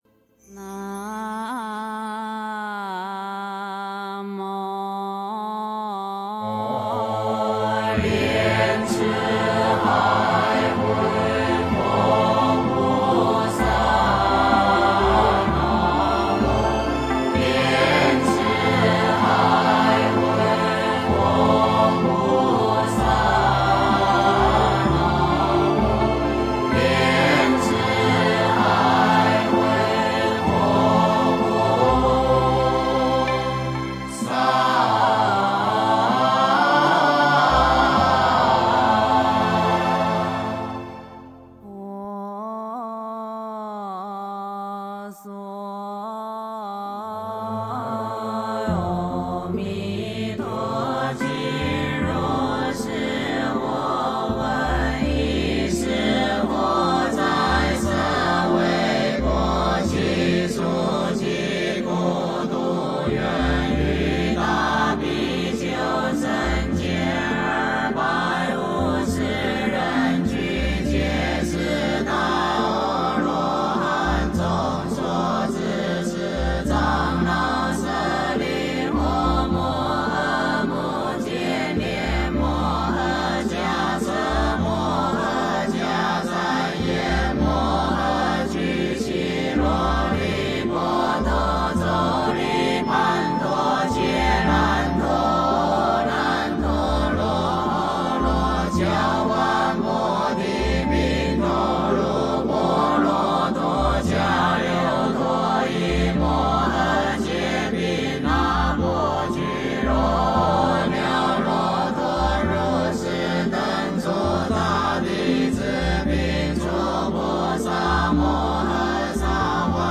佛说阿弥陀经 诵经 佛说阿弥陀经--如是我闻 点我： 标签: 佛音 诵经 佛教音乐 返回列表 上一篇： 第一时白文 下一篇： 观音佛号 相关文章 忏悔偈(梵乐)--未知 忏悔偈(梵乐)--未知...